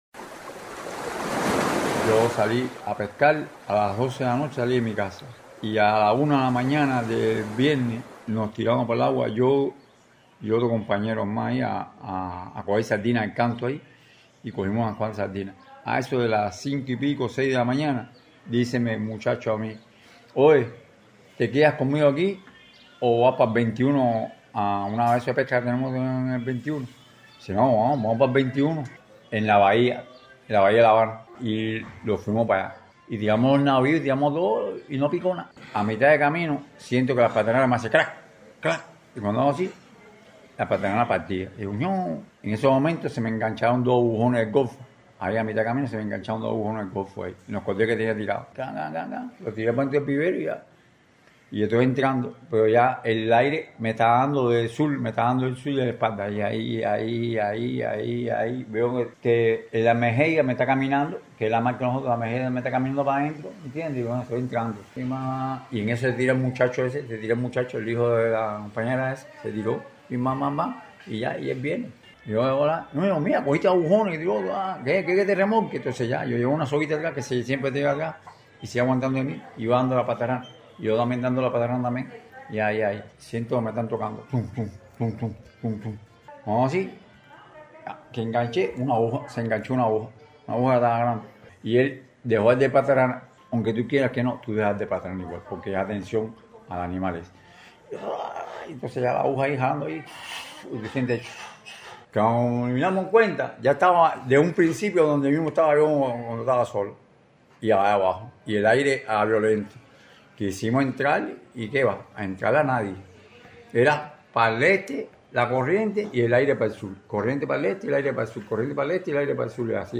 TESTIMONIO-PESCADOR-TERMINADO.mp3